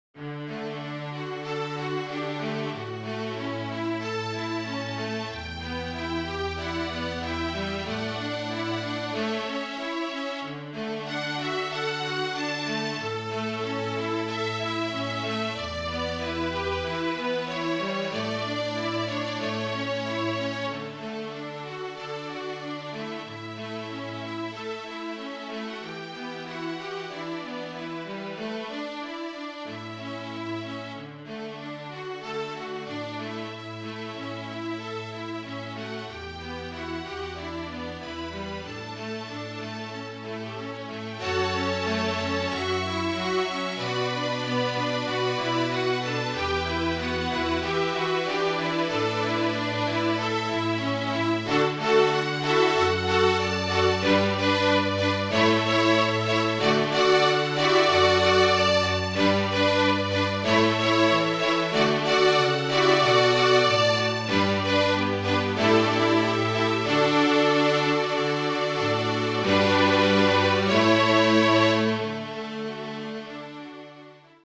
Flute, Violin and Cello (or Two Violins and Cello)
(Flute Trio)